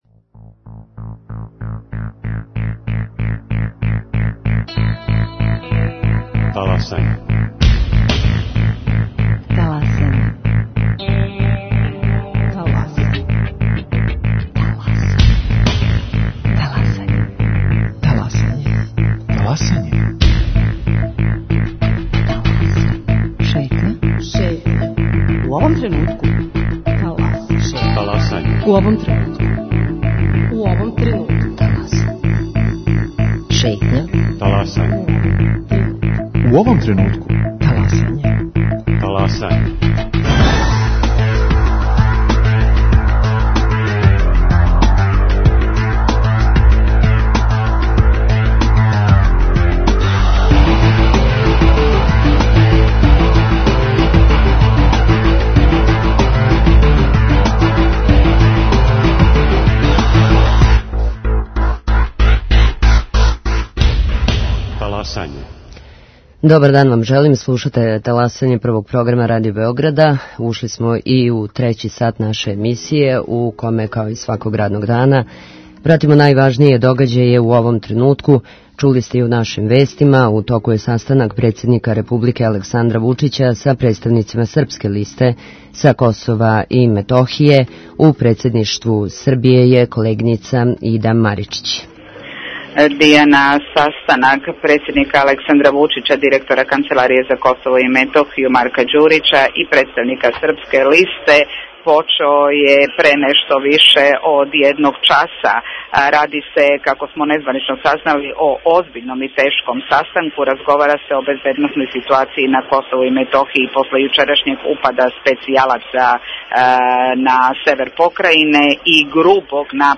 У емисији можете чути укључења наших репортера, директан пренос конференције за новинаре из Председништва Србије, као и наше госте који аналазирају одлуку Српске листе да изађе из косовске владе, као и да ће десет већински српских општина на Косову и Метохији 20. априла формирати Заједницу српских општина.